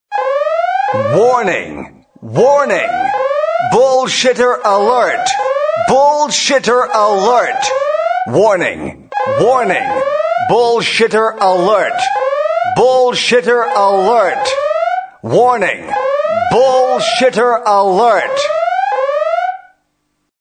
warning_bullshitter_alert.mp3